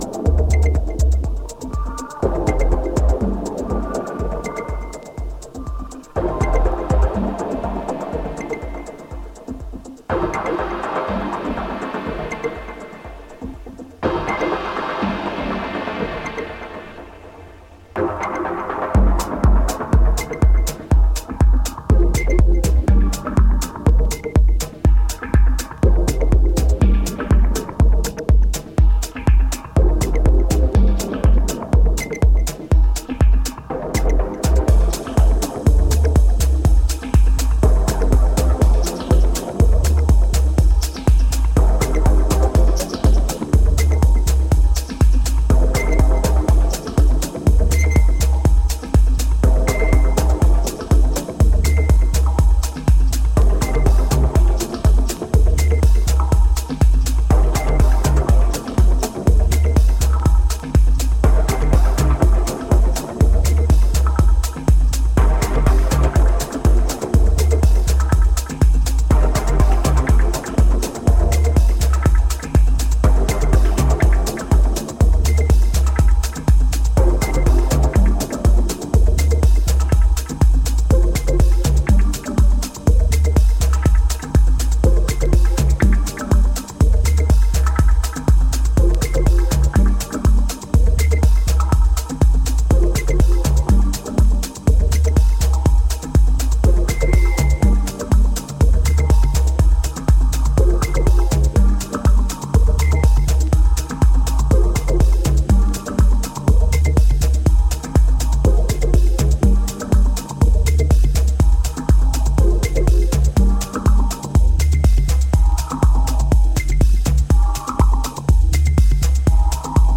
跳ねるハットと図太くうねるサブベース、神秘的な響きのエフェクトでハメる122BPMダブ・ハウス